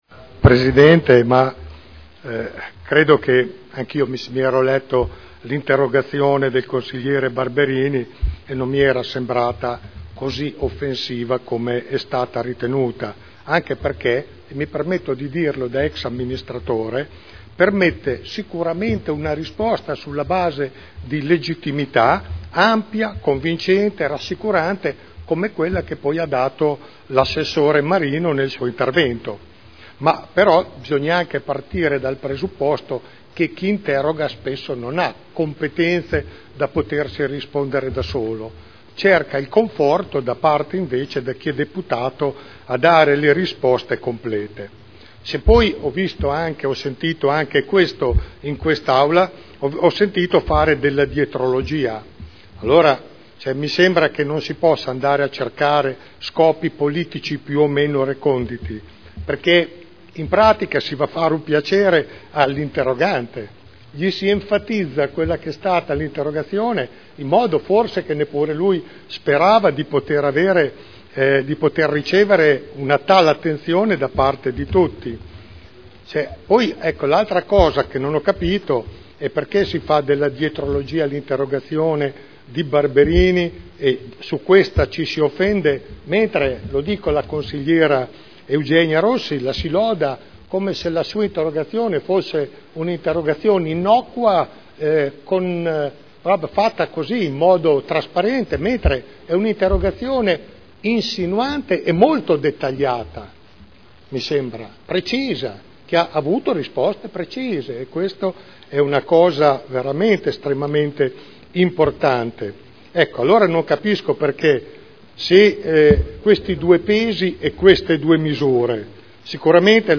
Giancarlo Pellacani — Sito Audio Consiglio Comunale